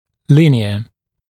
[‘lɪnɪə][‘линиэ]линейный, относящийся к линии